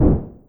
EXPLOSION_Subtle_Poof_02_stereo.wav